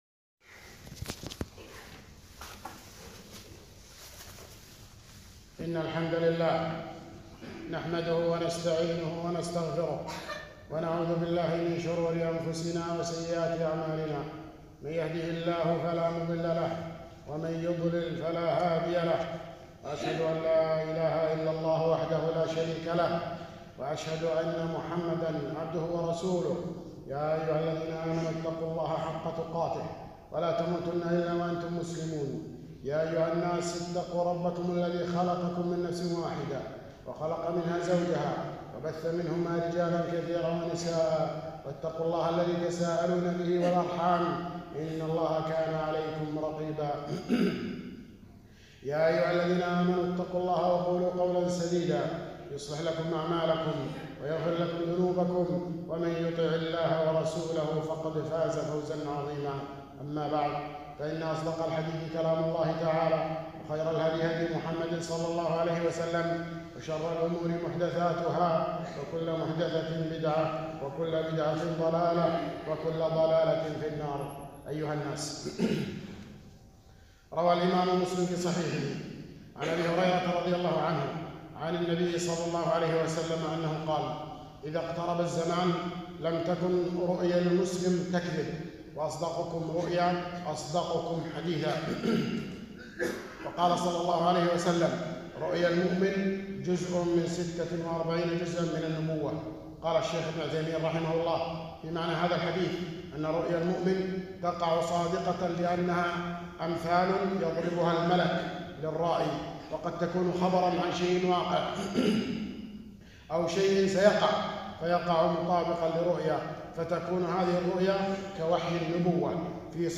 خطبة - الرؤى والأحلام